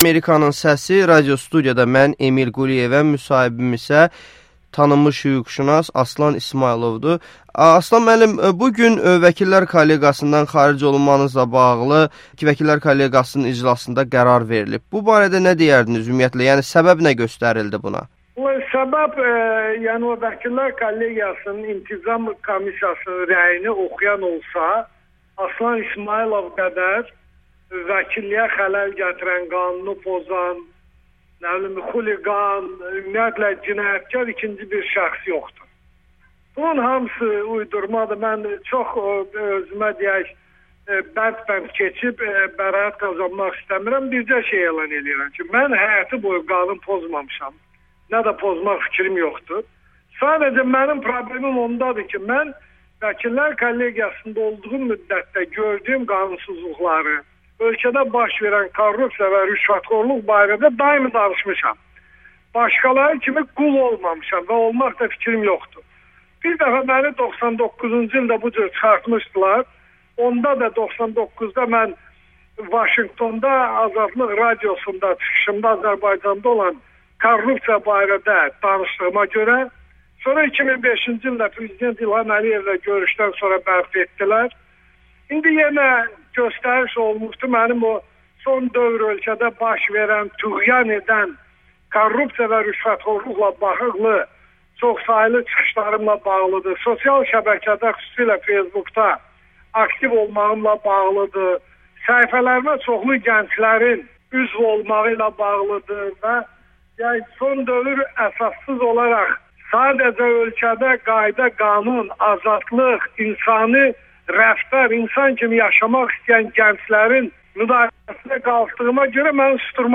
müsahibəsi
Tanınmış hüquqşunas Amerikanın Səsinə müsahibəsində onunla bağlı qərarların Kollegiya səviyyəsində qəbul edilmədiyini bildirib